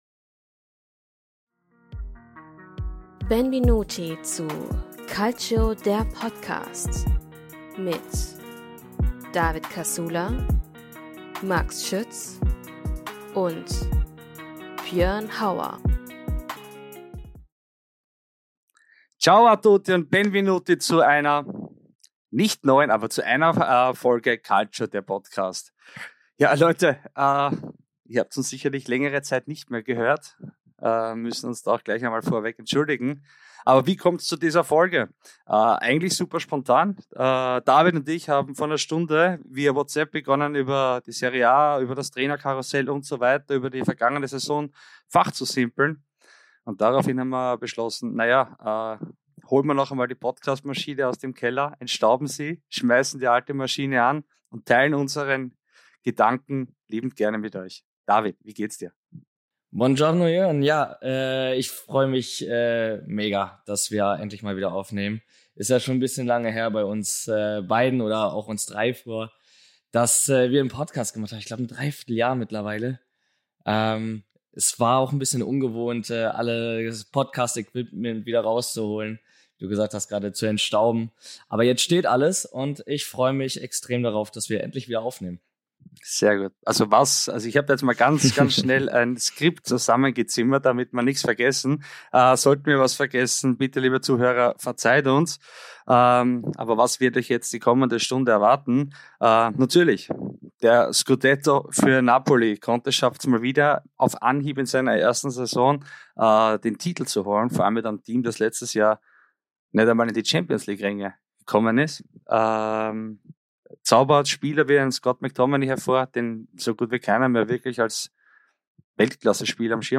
Seit der zweiten Saison durch einen Neuzugang verstärkt – Die Dreierkette am Mikrofon.